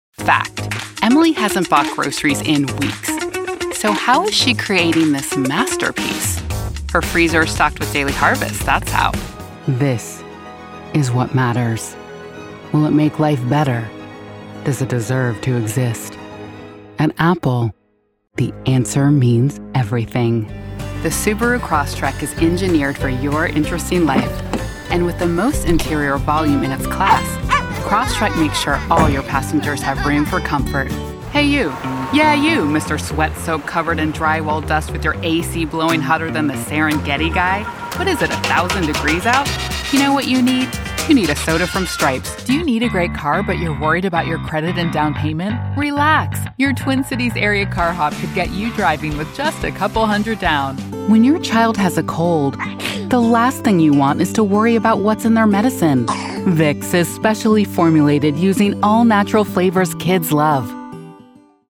English (American)
Natural, Distinctive, Accessible, Warm, Deep
Commercial